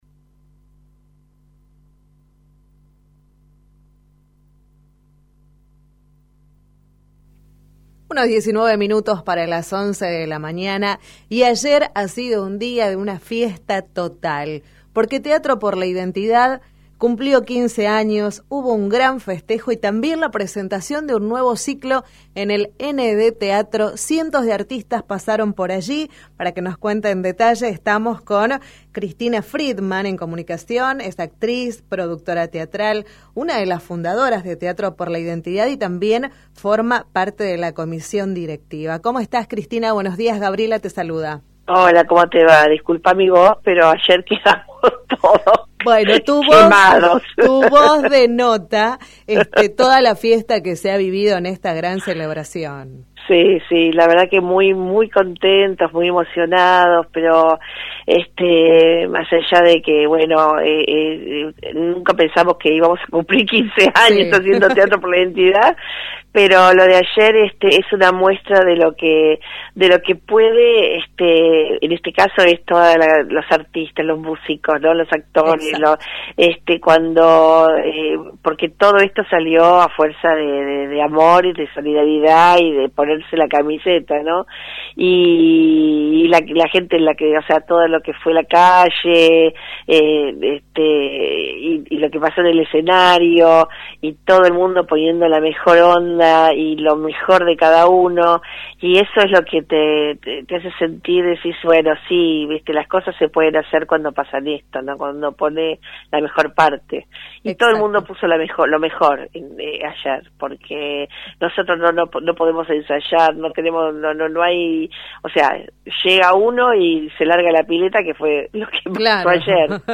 Conversamos sobre el festejo por los 15 años de Teatro x la Identidad y la presentación del nuevo ciclo